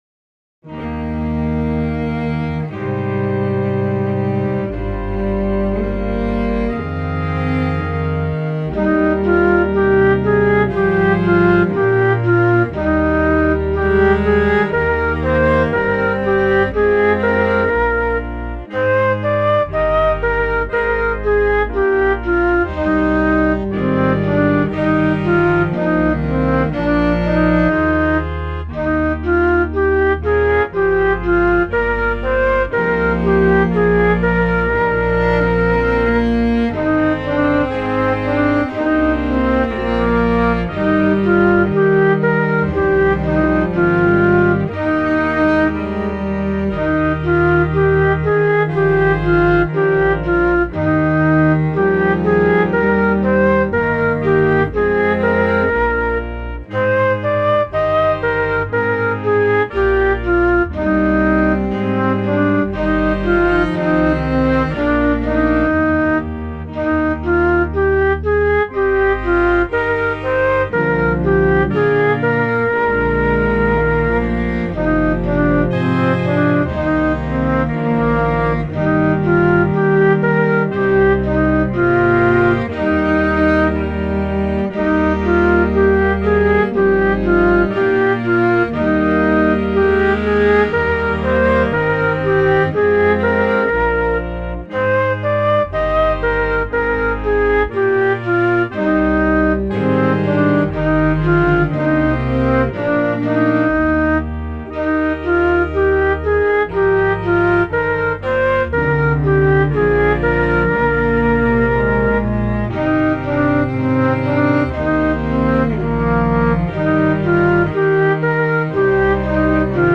My backing is a bit of a fail (BIAB and chant don’t like each other), but the text is a translation of a fourth century work by Aurelius Prudentius, which is not long after Christmas started becoming an important feast, so I thought I’d mention it anyway.
The tune is Plainsong from the thirteenth century – DIVINUM MYSTERIUM.
I guessed some spaces and it doesn’t sound right to me: